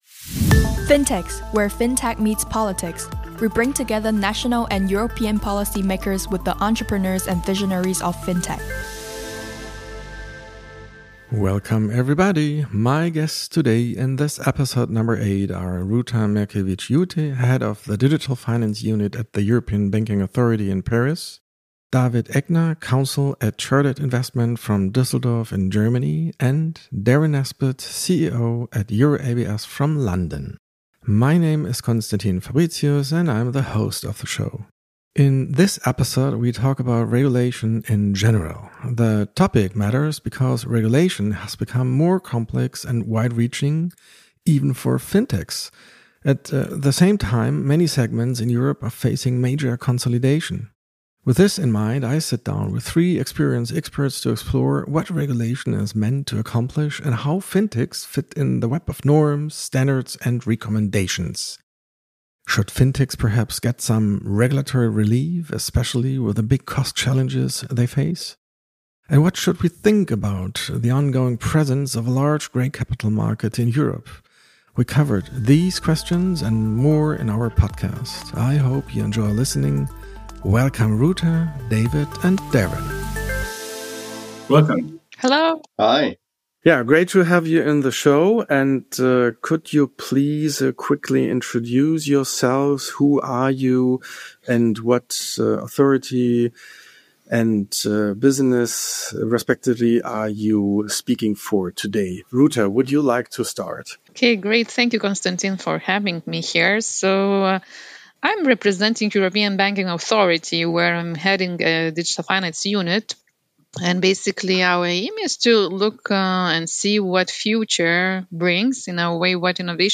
In this episode, three seasoned experts review the past, the present and the future from different perspectives.